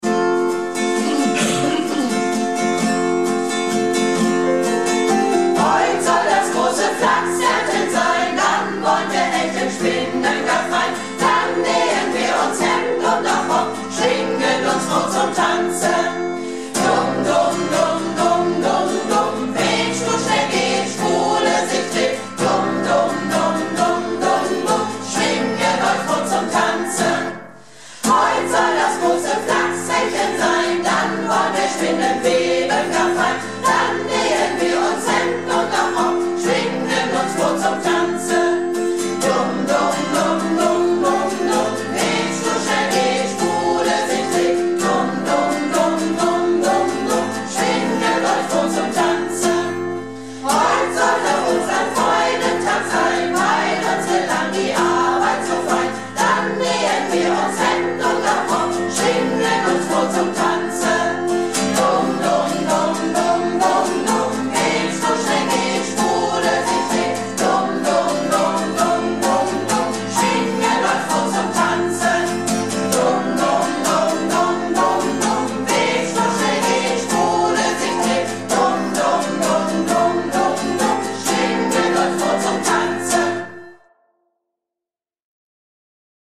Malle Diven - Probe am 06.09.17